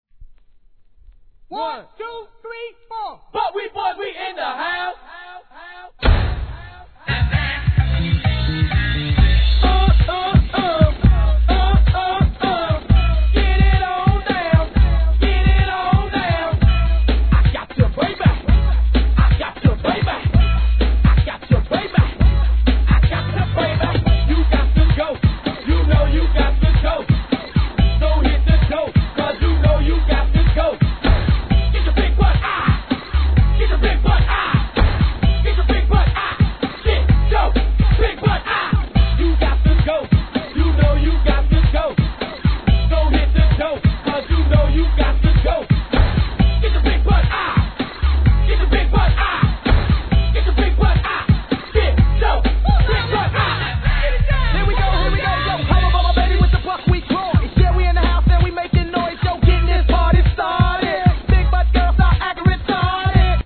HIP HOP/R&B
OLD SCHOOLのPARTYチュ〜ン定番